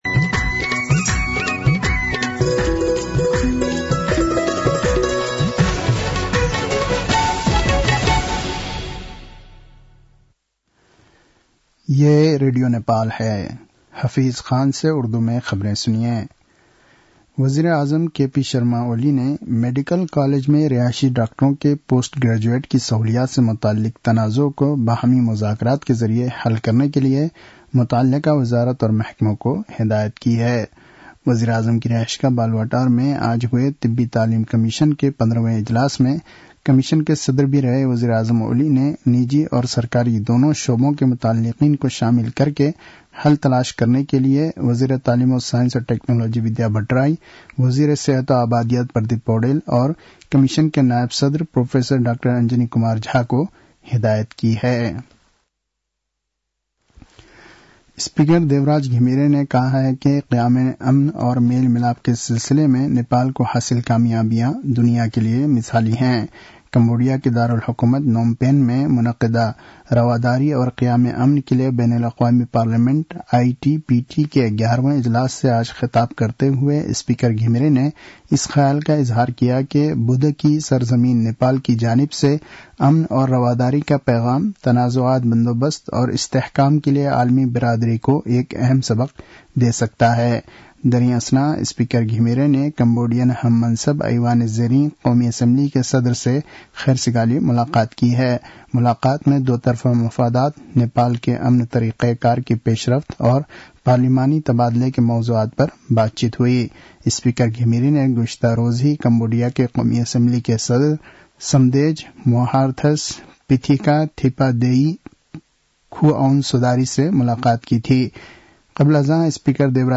उर्दु भाषामा समाचार : १० मंसिर , २०८१
Urdu-News-8-9.mp3